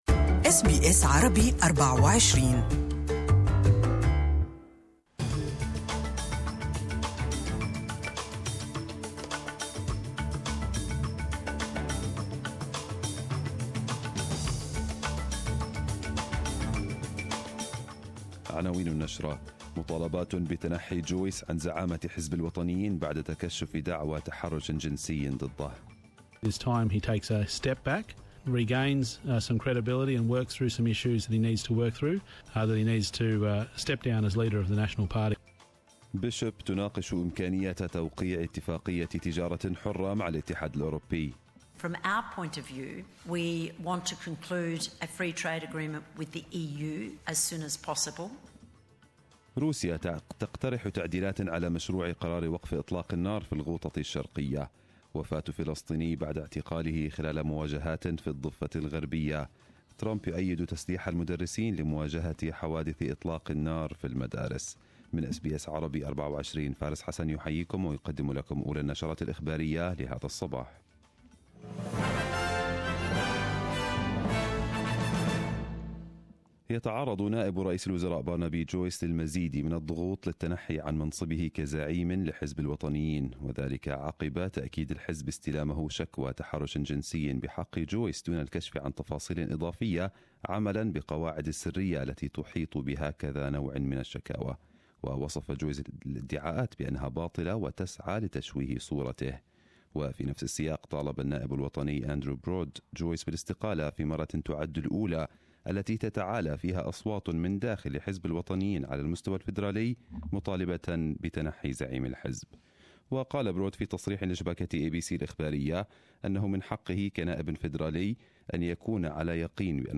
Arabic News Bulletin 23/02/2018